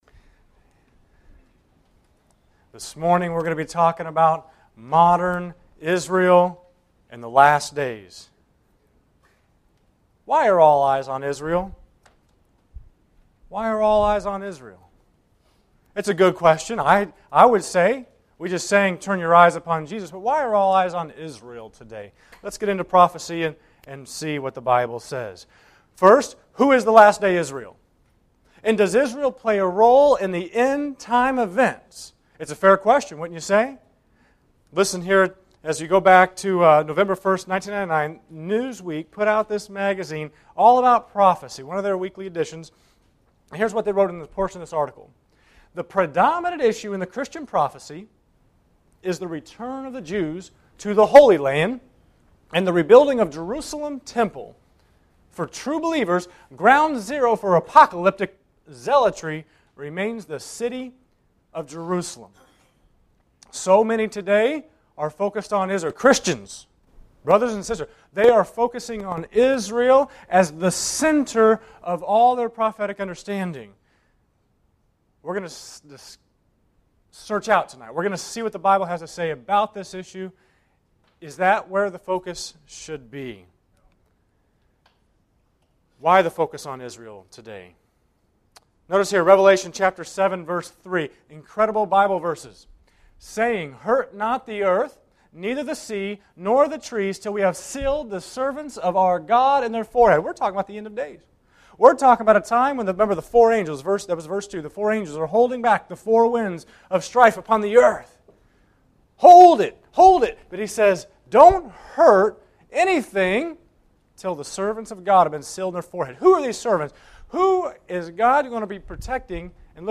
Genre: Prophecy Seminar.